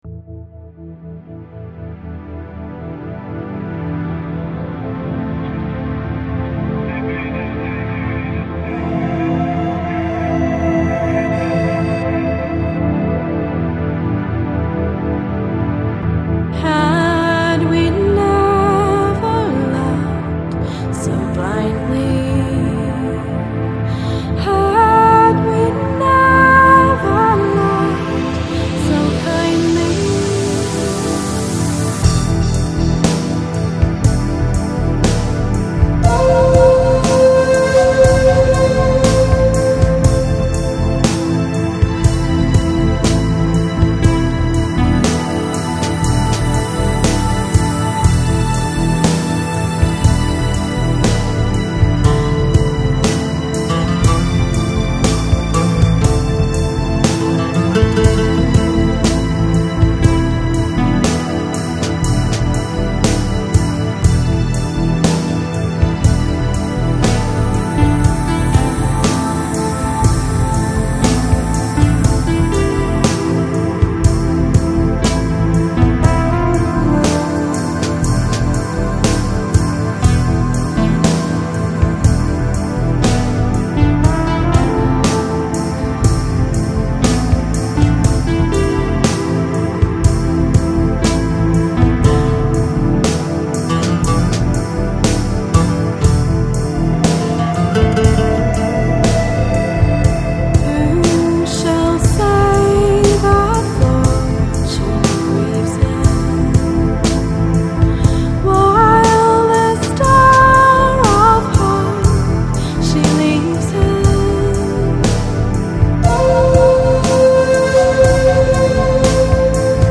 Lounge, Chill Out, Downtempo, Ambient Год издания